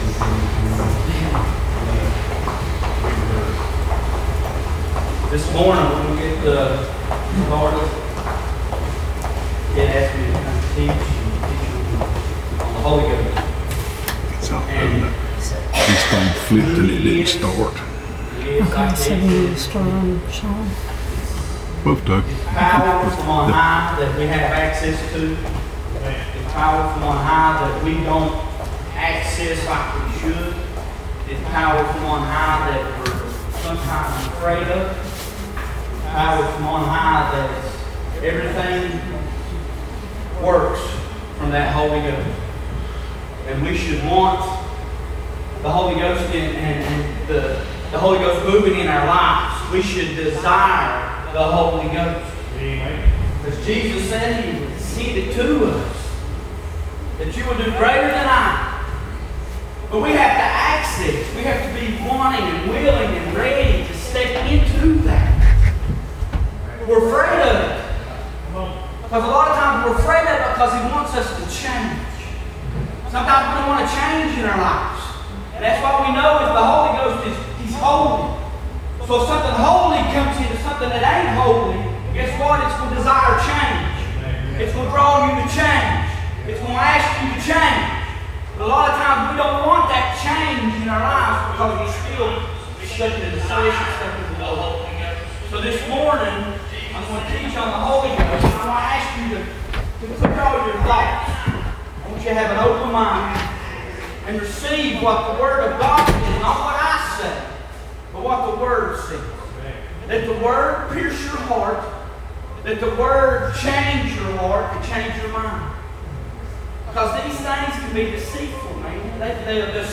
Listen to "Sunday Morning Teaching" from Mallory Church of God.
Sunday Morning Teaching